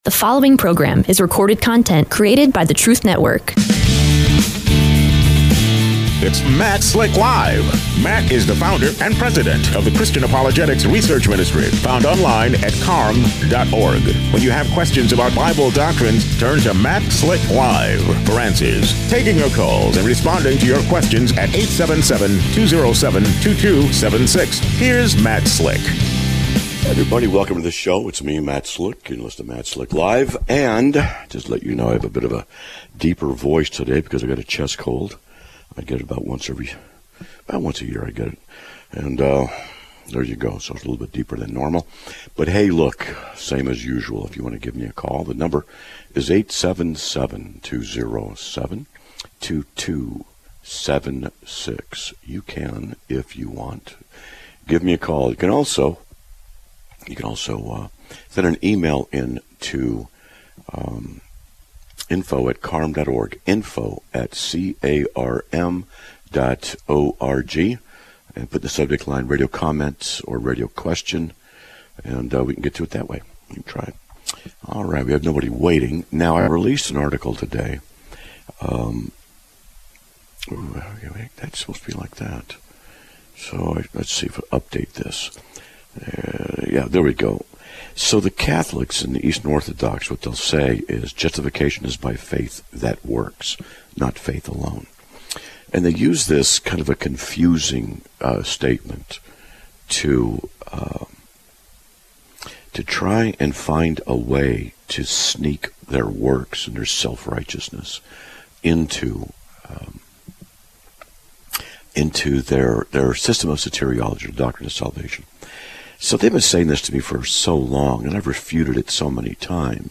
A Caller Discusses His Church Experience, and What Parishioners Need